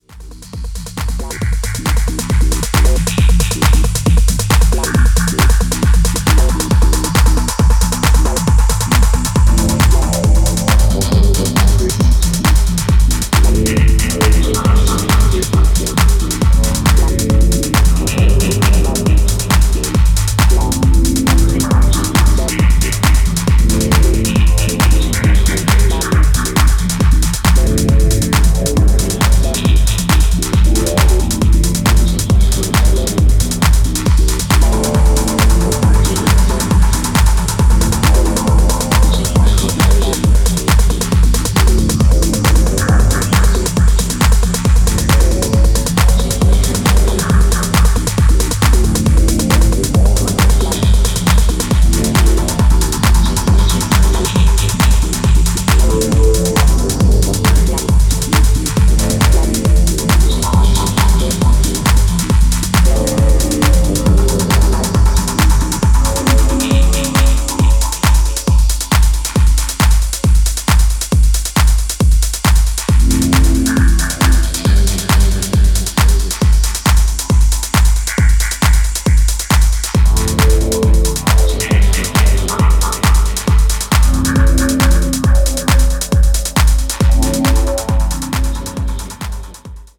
ストンピンなグルーヴとアトモスフェリックなコードが深夜帯のフロアを掌握するA-1がまずはディープなキラーチューン。